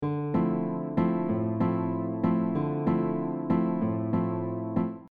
This mellow and beautiful style uses syncopated rhythms with a fingerpicking style of playing.
This particular Bossa Nova pattern places all of the upper notes of the chord in the upbeats.
Bossa nova rhythm variation - chord on upbeats
Bossa-Nova-pattern-4.mp3